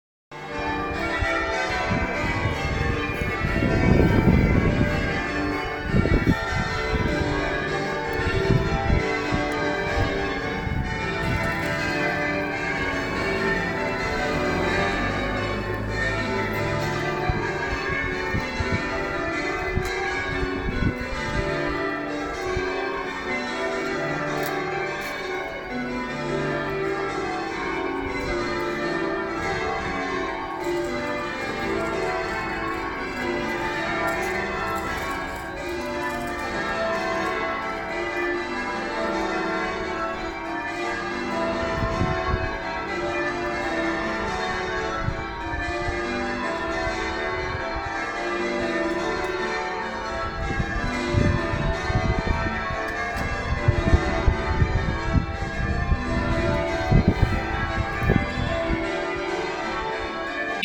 The bells of St Mary the Great, recorded 9am 17th November 2024
st-mary-bells.mp3